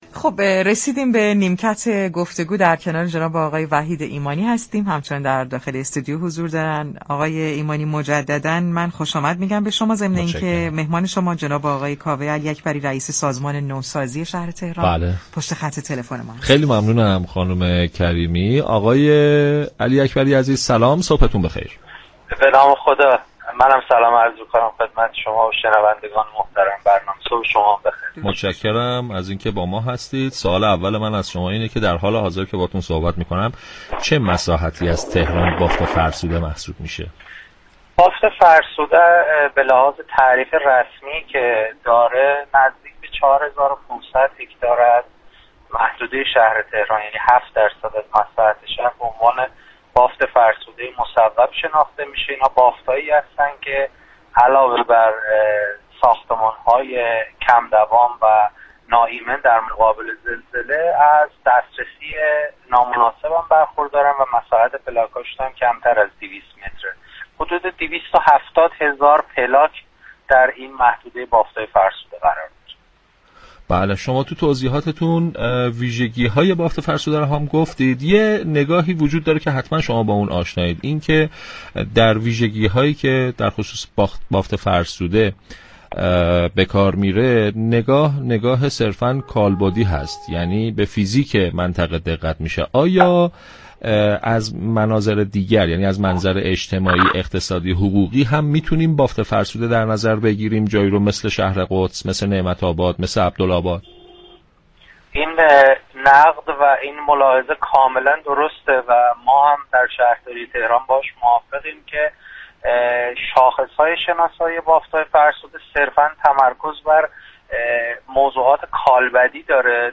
در گفتگو با پارك شهر رادیو تهران